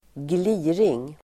Uttal: [²gl'i:ring]